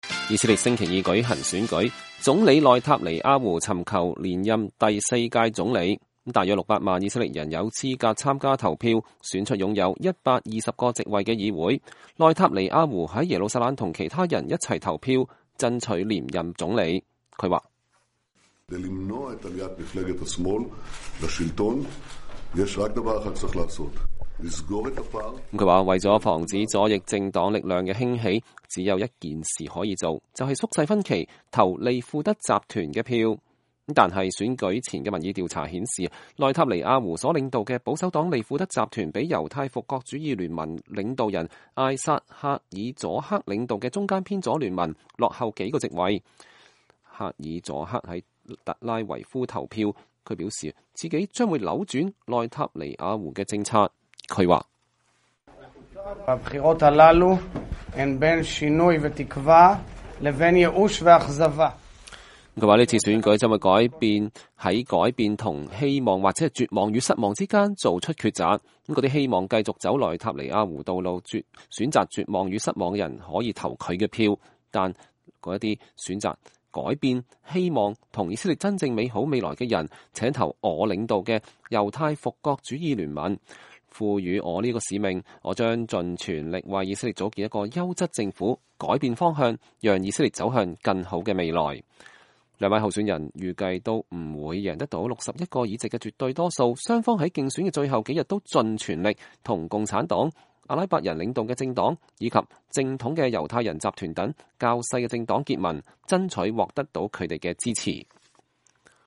內塔尼亞胡投票後對記者講話